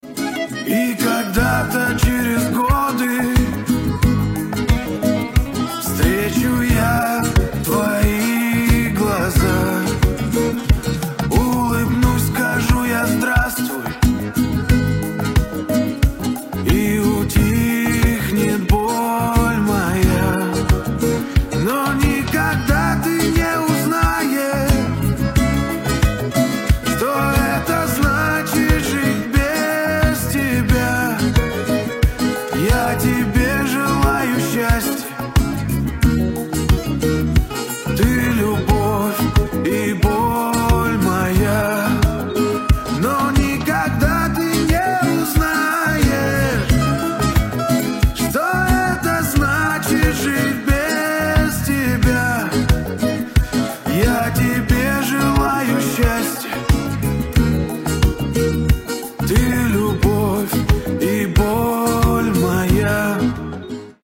Кавер